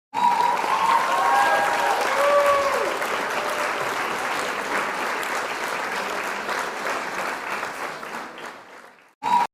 دانلود صدای دست زدن 3 از ساعد نیوز با لینک مستقیم و کیفیت بالا
جلوه های صوتی
برچسب: دانلود آهنگ های افکت صوتی انسان و موجودات زنده دانلود آلبوم صدای دست زدن و تشویق از افکت صوتی انسان و موجودات زنده